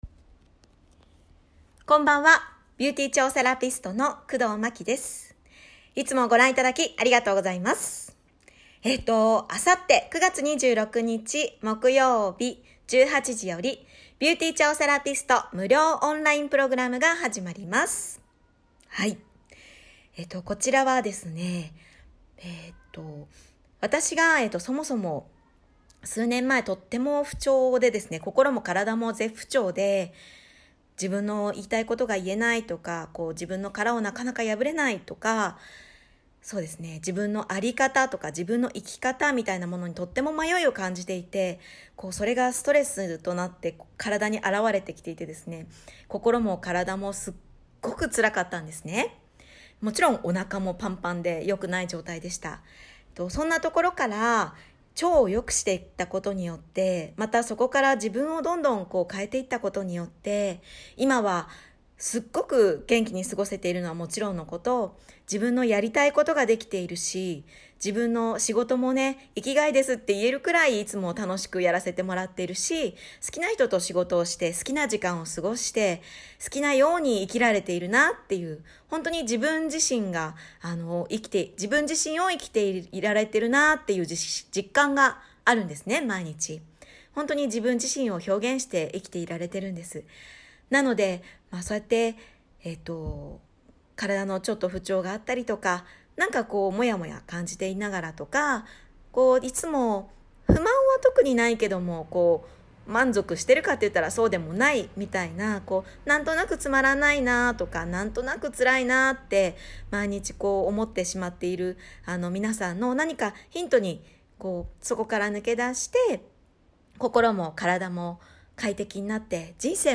ひとりで喋るのって、